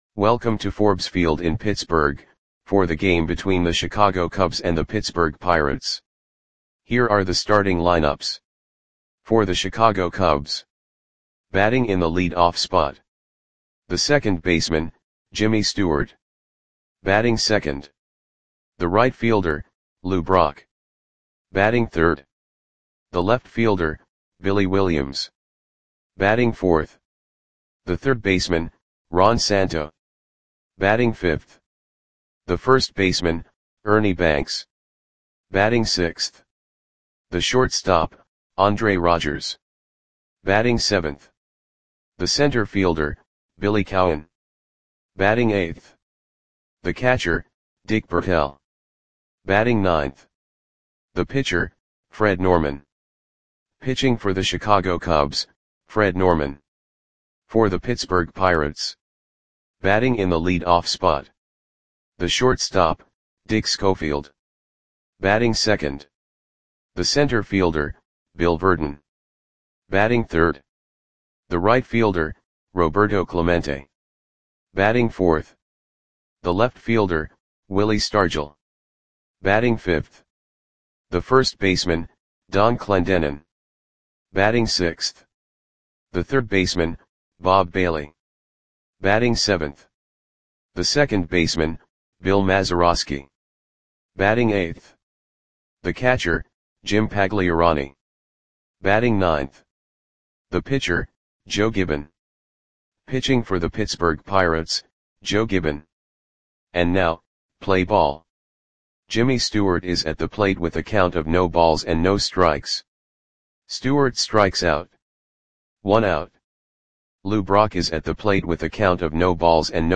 Audio Play-by-Play for Pittsburgh Pirates on April 15, 1964
Click the button below to listen to the audio play-by-play.